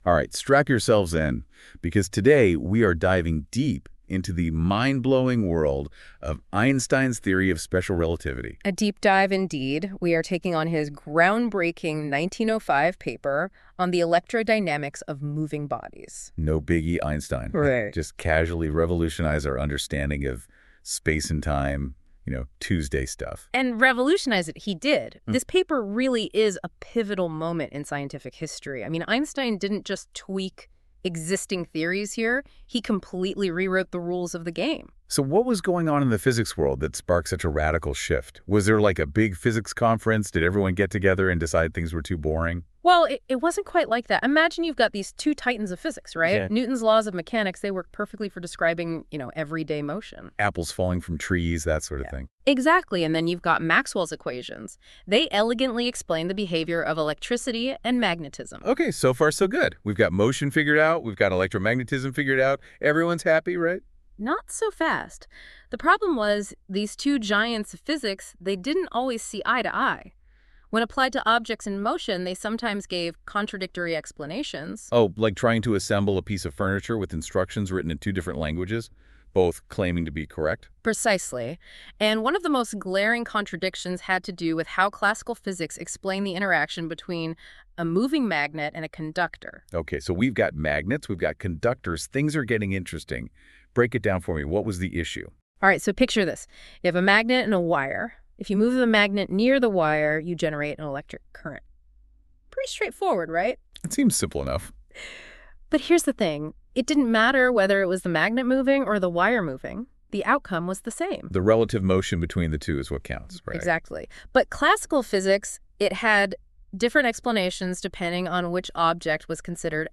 The AI-generated voices break down the complex concepts of special relativity into an informal and engaging conversation, making the physics of moving trains, light beams, and time travel feel relatable.
Prepare to rethink what you thought you knew about reality, all through a conversation that’s as human as it is artificial.